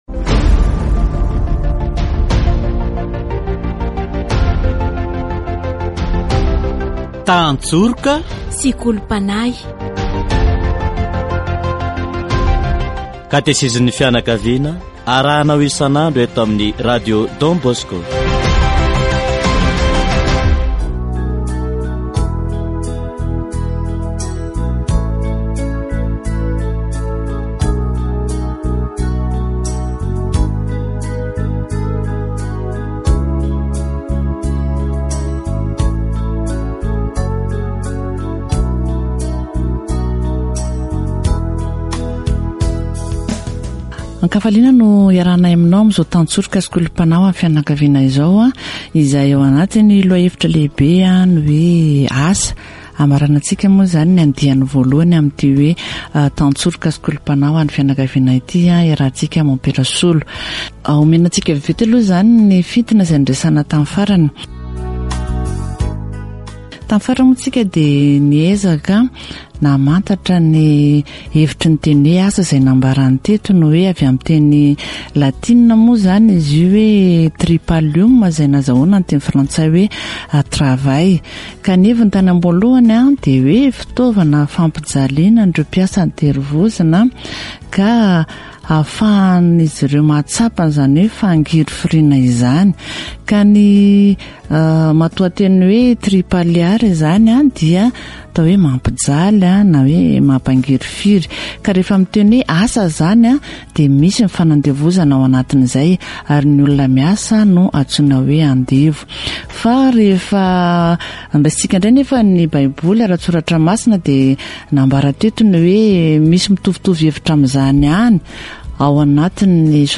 Catéchèse sur le travail